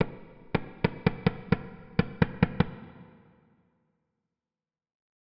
FireWorks.wav